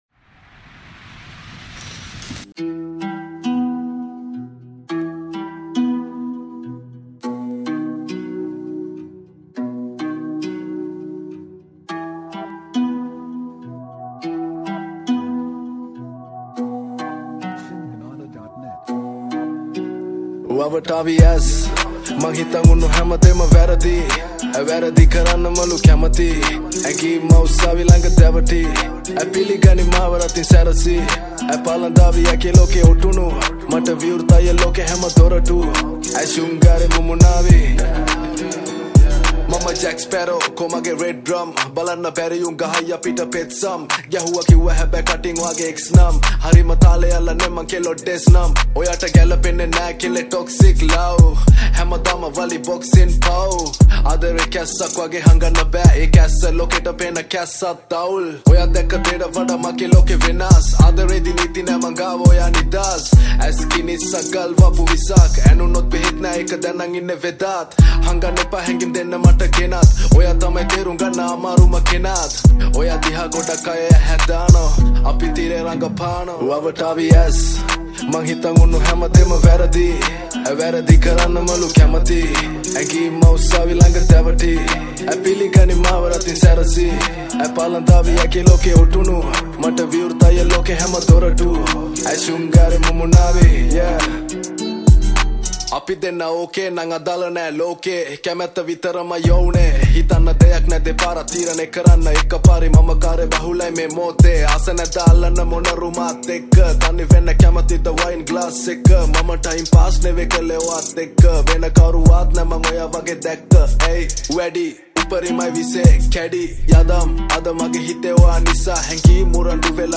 Rap Songs